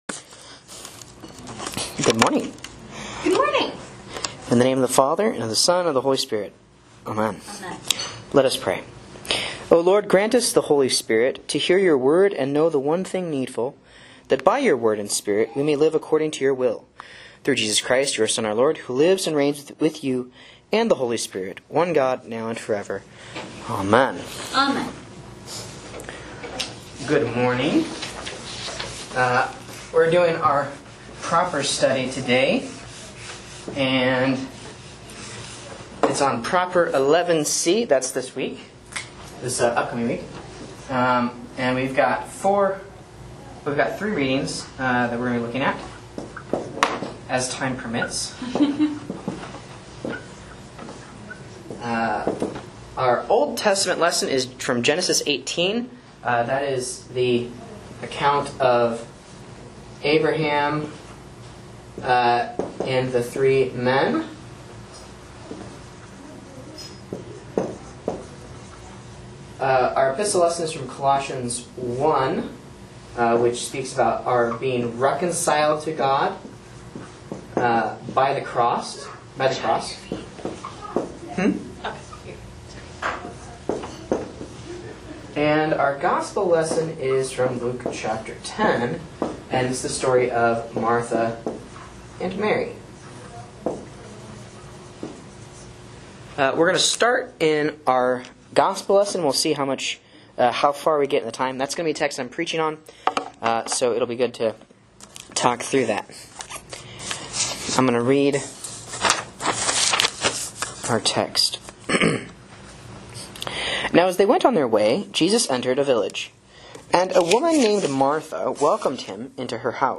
A Bible Class on Luke 10.38-42 for Proper 11 (C)